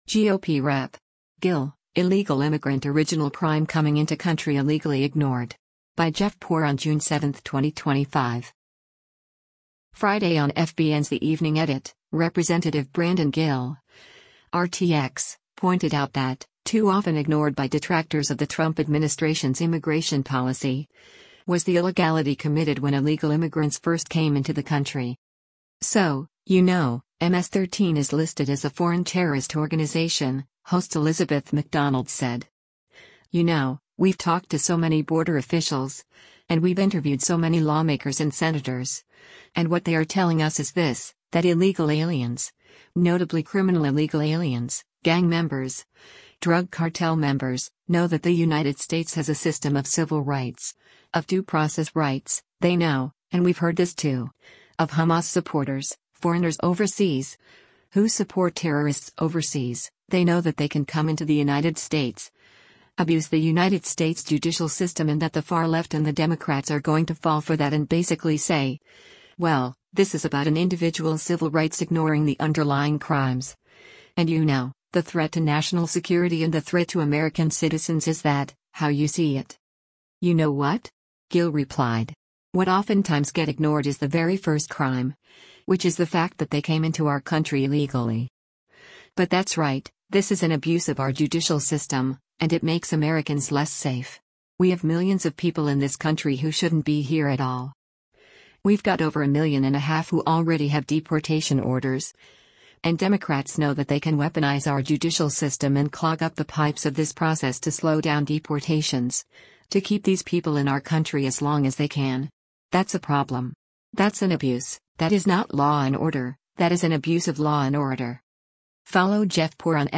Friday on FBN’s “The Evening Edit,” Rep. Brandon Gill (R-TX) pointed out that, too often ignored by detractors of the Trump administration’s immigration policy, was the illegality committed when illegal immigrants first came into the country.